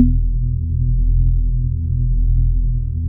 VIBE HIT B-R.wav